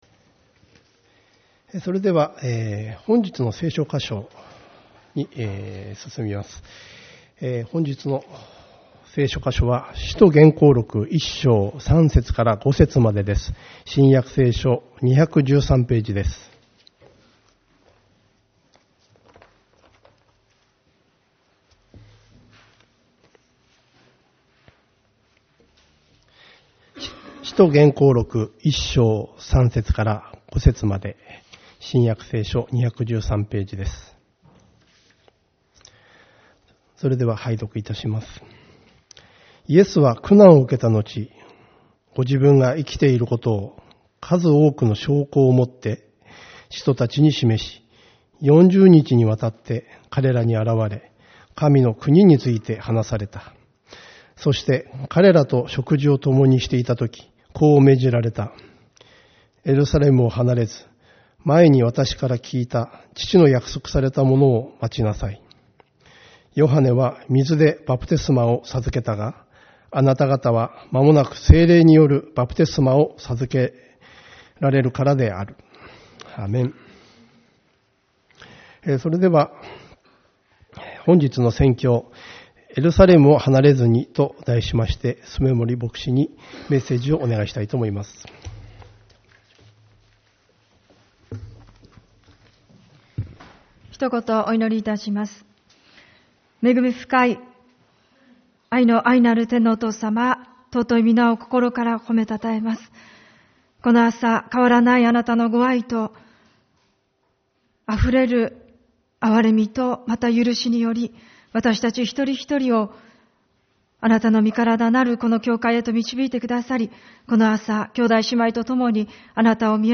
主日礼拝 「エルサレムを離れずに」